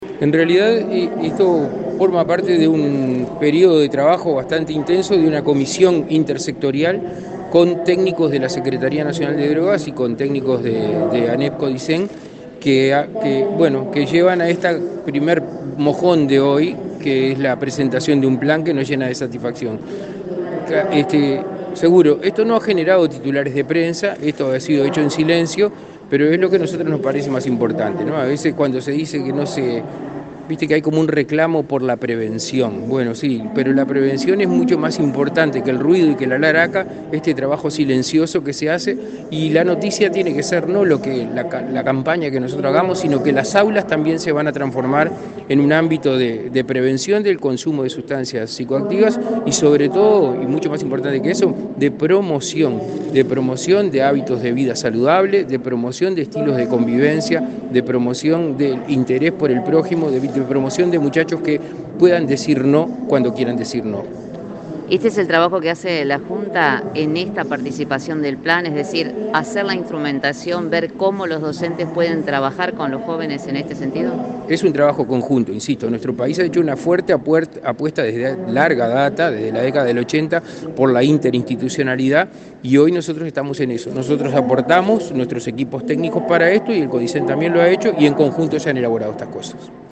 Entrevista al secretario de la Junta Nacional de Drogas, Daniel Radío